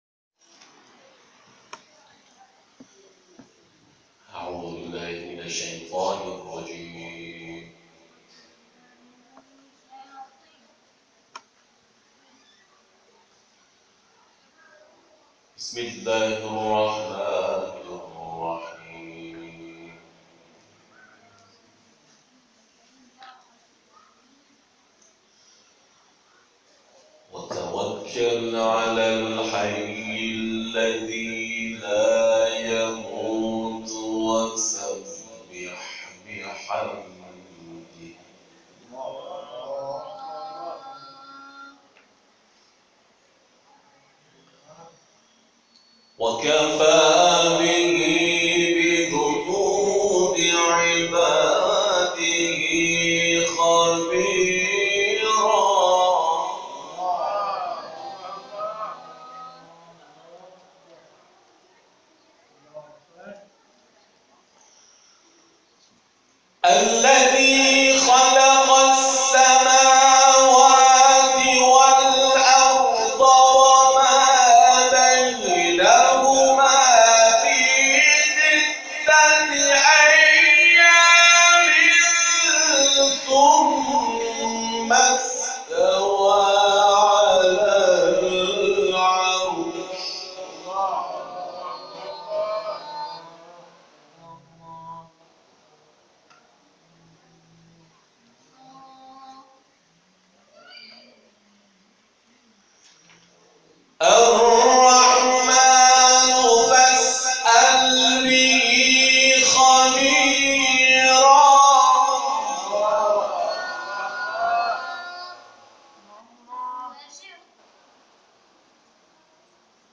تلاوت
در امامزاده عیسی(ع)